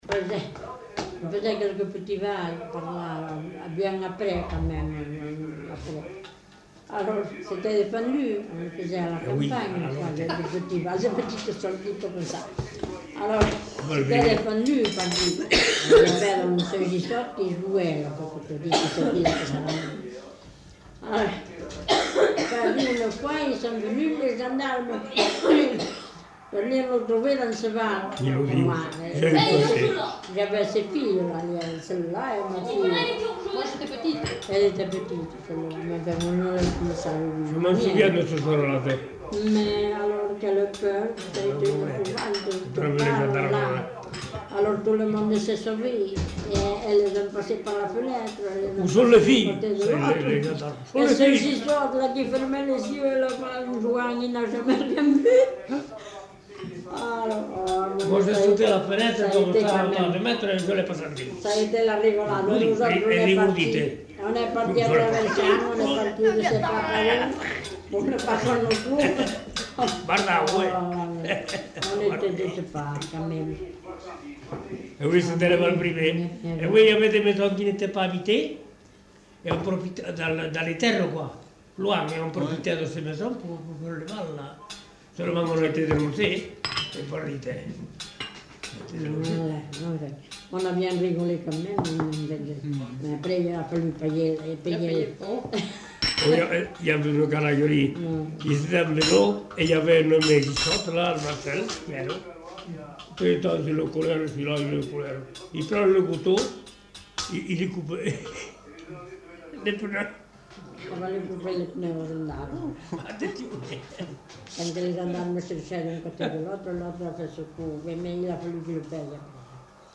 Lieu : Pavie
Genre : témoignage thématique